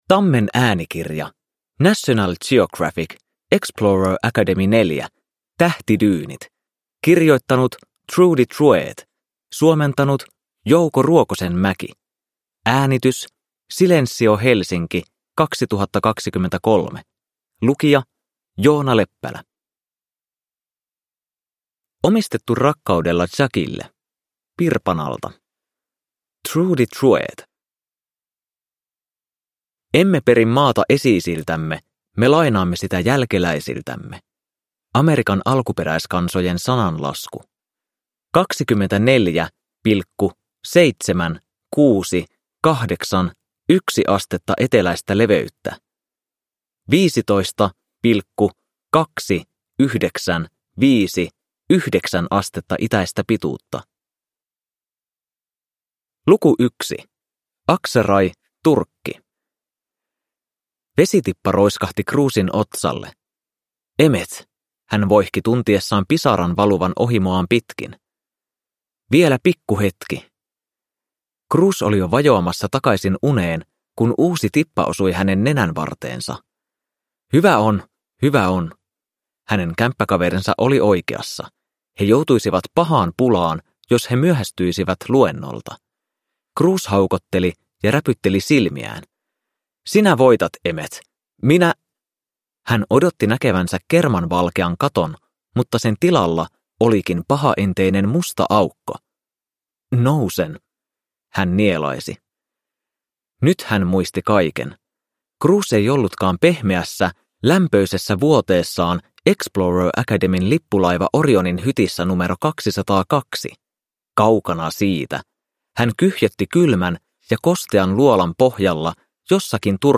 Explorer Academy 4. Tähtidyynit – Ljudbok